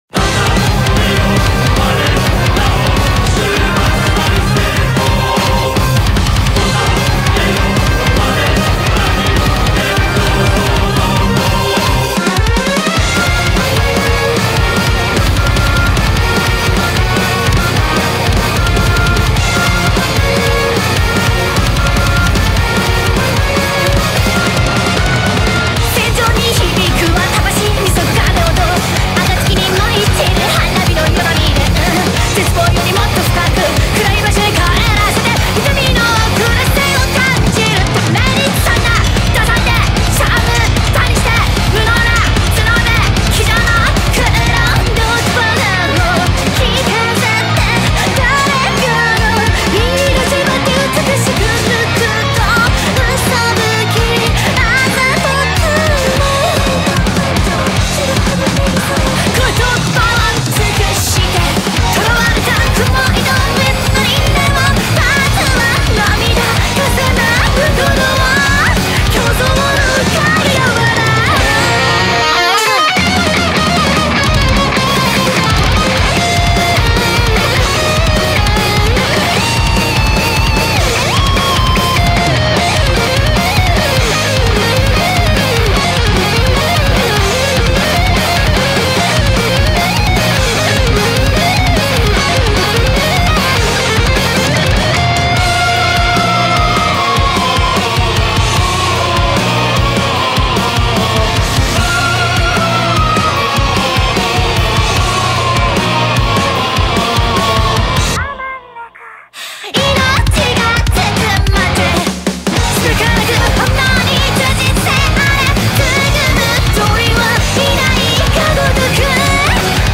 BPM150
Audio QualityPerfect (High Quality)
some weird time signature stuff in here